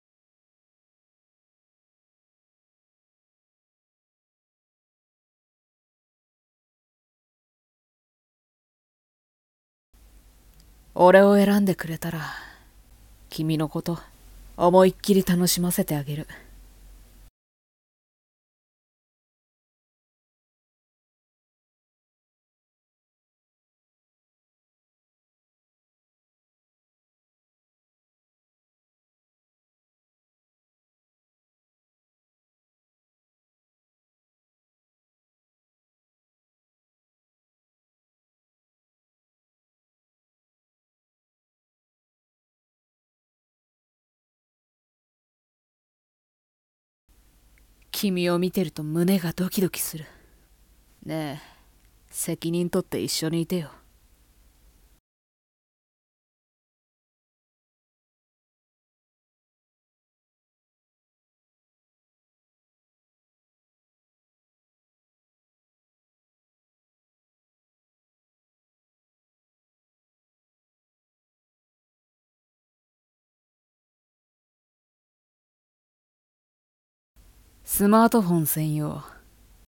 【5人声劇】恋するカカオと4人の王【バレンタイン】